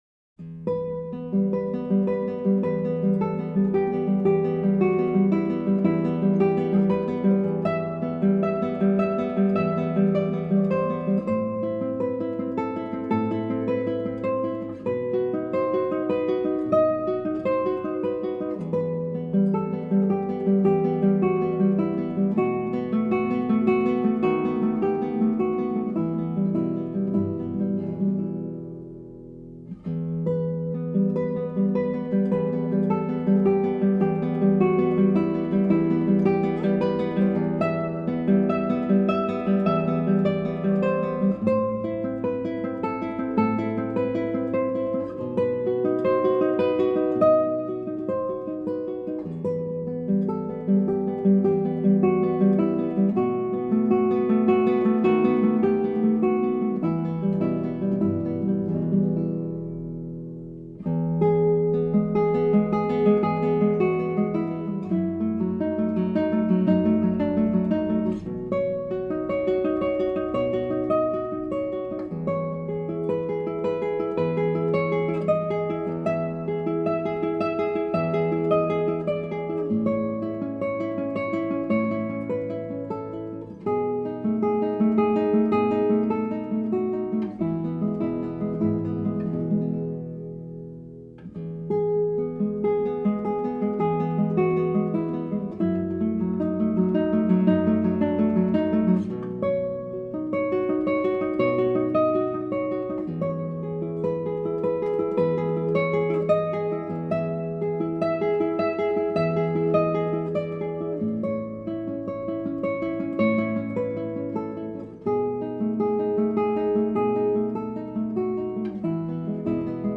Classical Songs , Spanish Songs